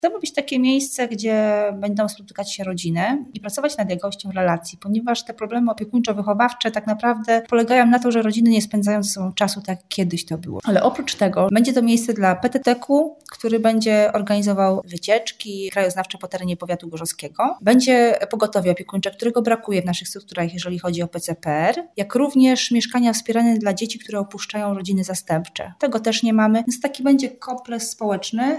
Teraz będzie to miejsce, które spełni funkcje promujące rodzinę i relacje społeczne, ale obiekt będzie też pełnił wiele innych funkcji – mówi Magdalena Pędziwiatr, starosta powiatu gorzowskiego.